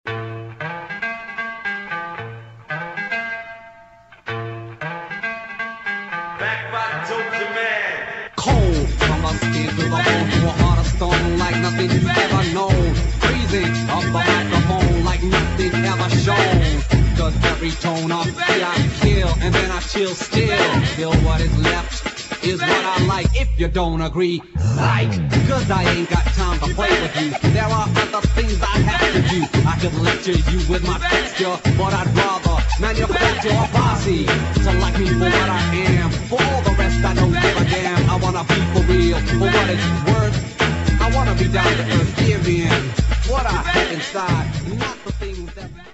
1. HOUSE | DISCO